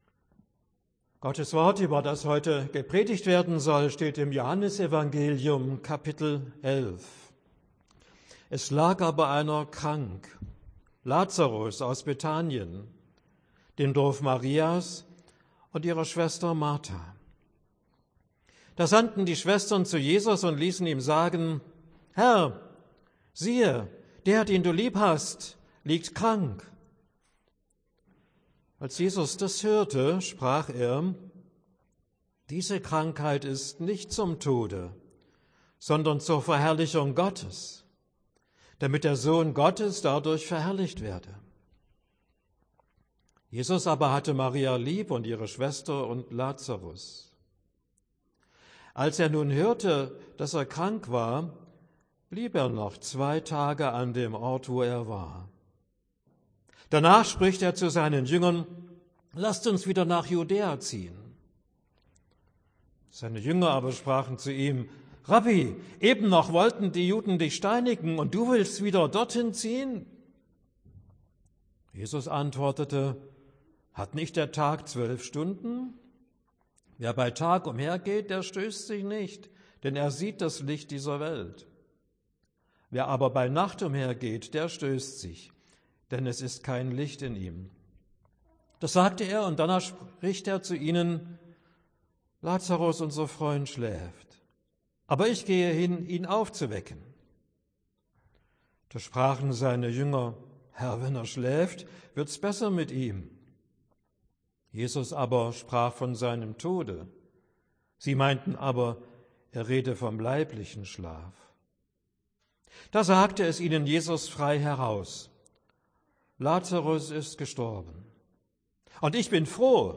Predigt für den 16. Sonntag nach Trinitatis